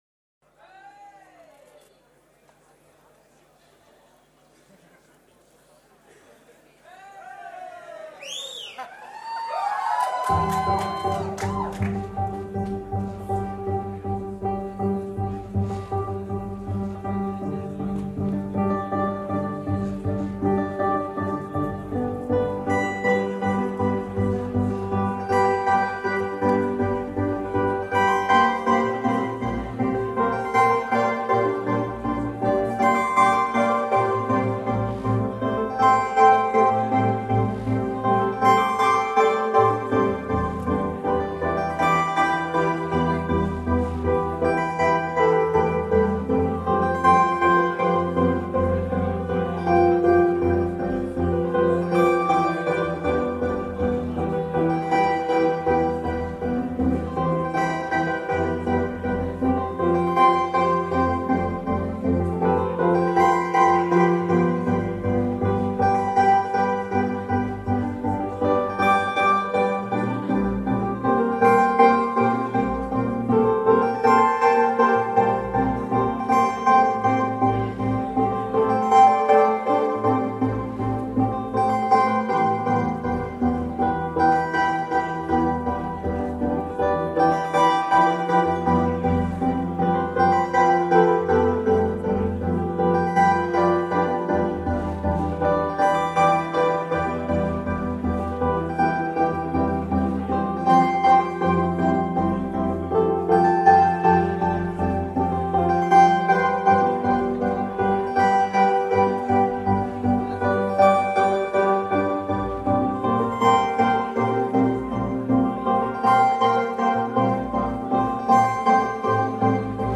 venue The Old Truman Brewery